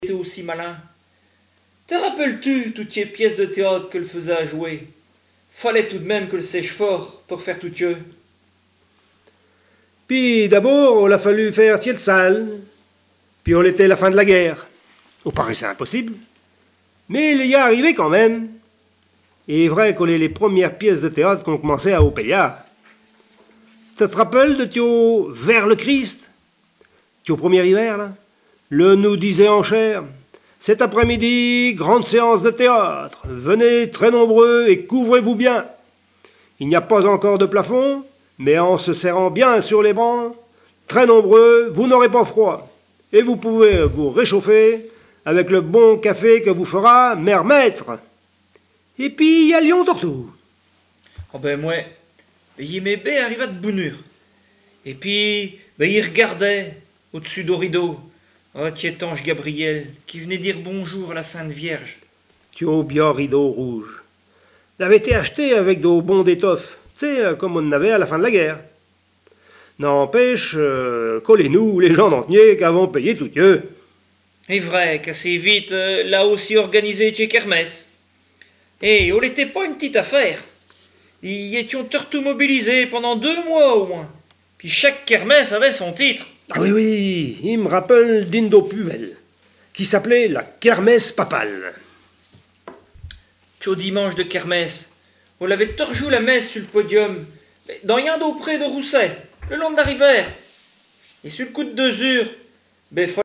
Langue Patois local
Genre sketch